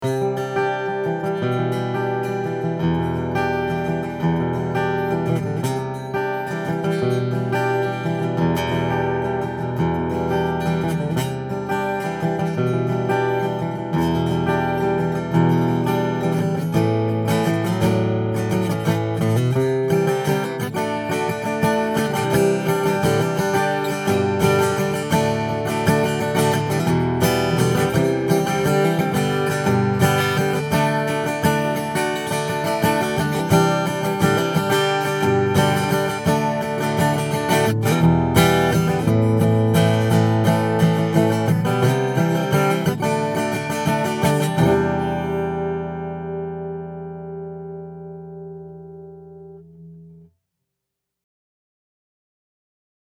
All of the clips are with the guitar plugged directly into my pre-amp going into my DAW.
I recorded the individual images with the image mix cranked all the way up.